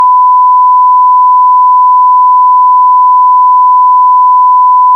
SONAR 3.1 に  1KHz のサイン波を読み込み、それぞれ各周波数に変換した後の波形を見てみました。
96KHz のサイン波は、YAMAHA 01ｘ から発振。